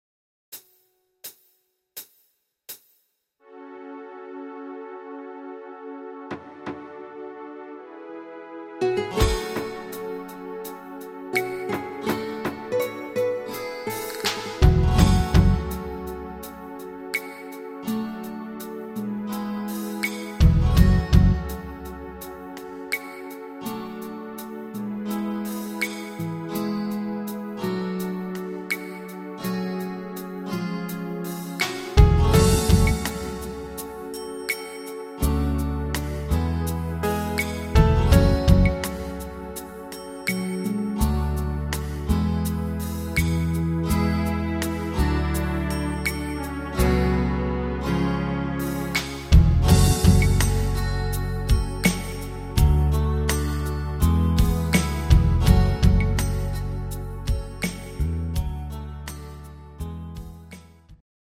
Rhythmus  Medium Pop
Art  Pop, Englisch